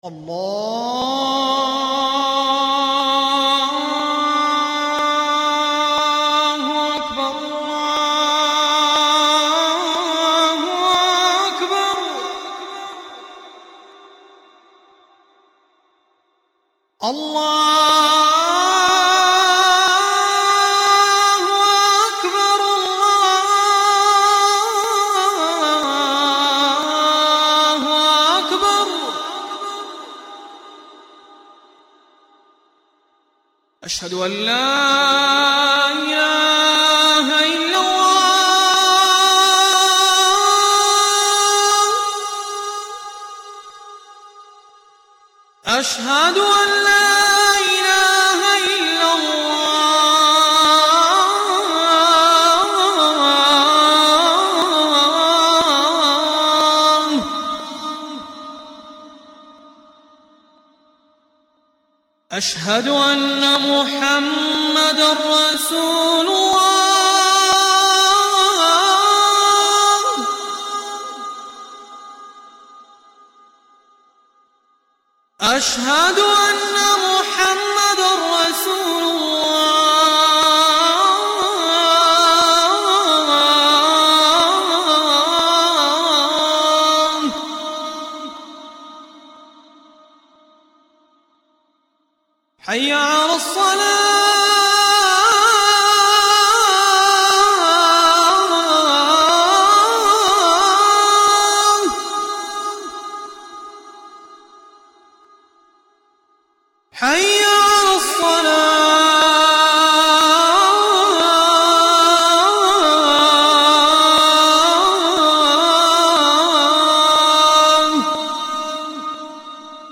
دانلود اذان عربستان
اذان-مکه-مکرمه-عبدالمجید-السریحی.mp3